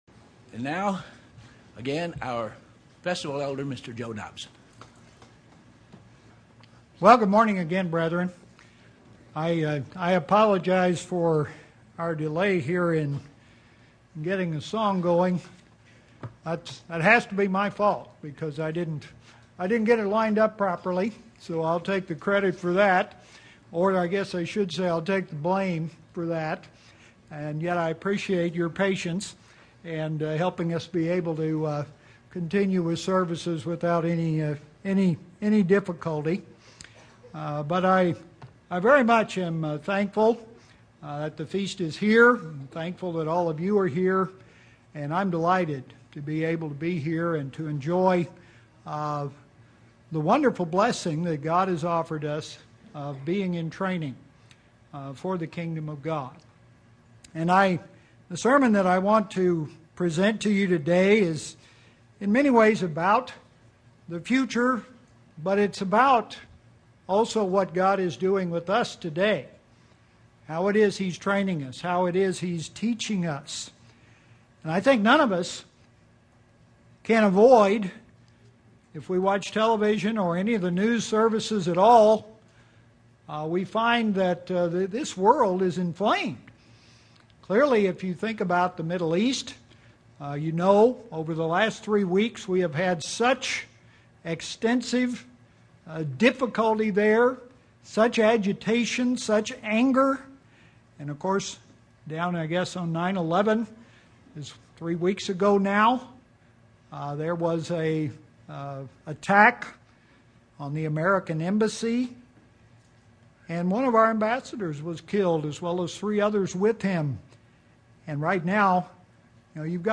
This sermon was given at the Branson, Missouri 2012 Feast site.